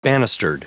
Prononciation du mot banistered en anglais (fichier audio)
Prononciation du mot : banistered